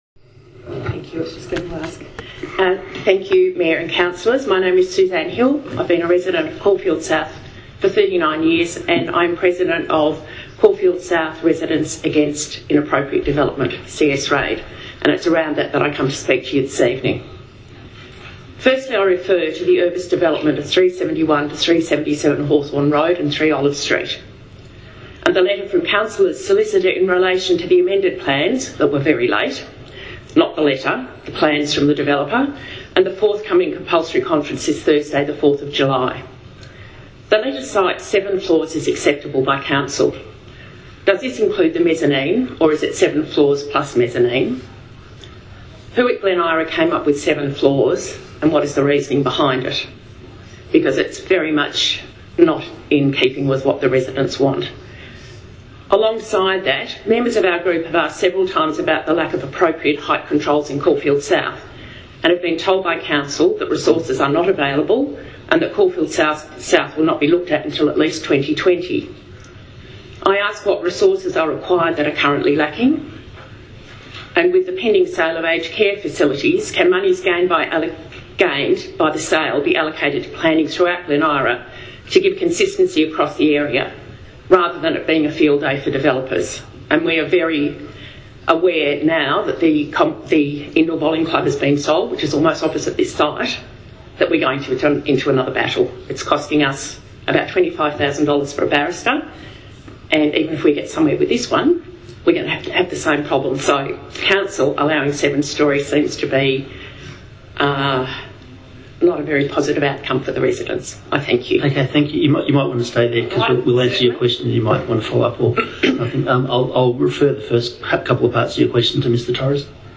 Please listen very carefully to the following audio from last night’s council meeting. It features one resident’s questions that exemplify everything that is wrong with this council.